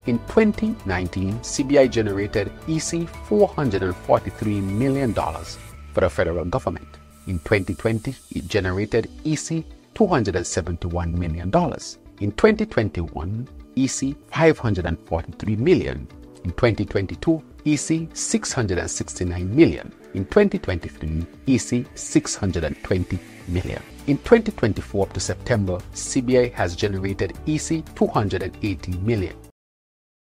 Prime Minister and Minister of Finance, Dr. Terrance Drew. He gave those figures in his national address on Oct. 17th and the total figure is EC $2, 764,000,000.